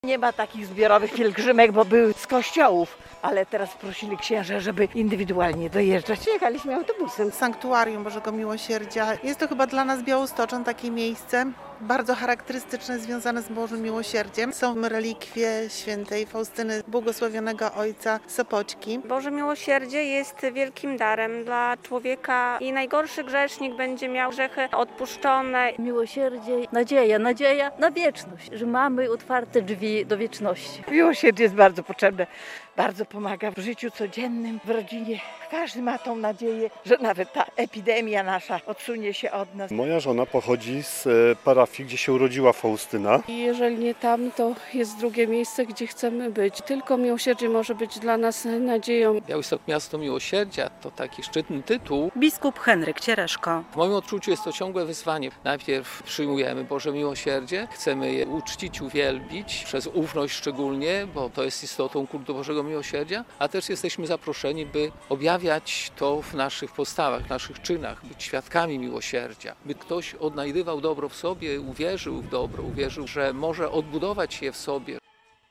Niedziela Miłosierdzia Bożego w Kościele katolickim - relacja